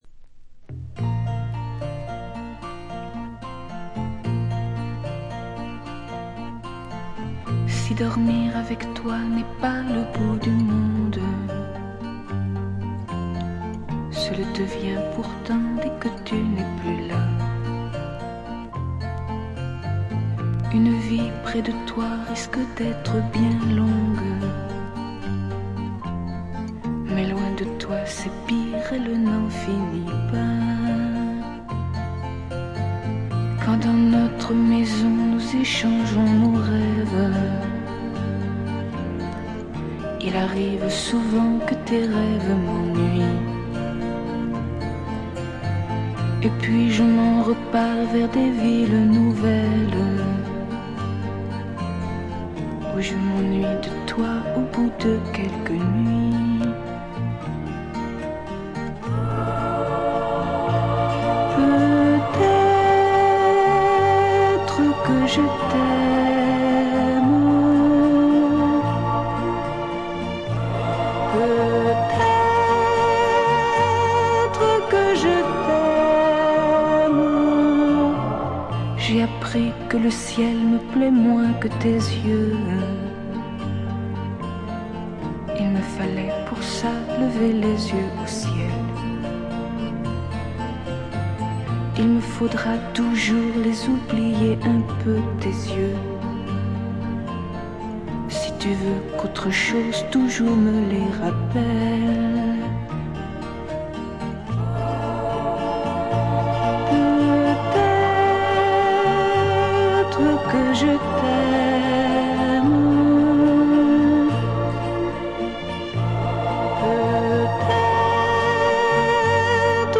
他はほとんどノイズ感なしで良好に鑑賞できます。
試聴曲は現品からの取り込み音源です。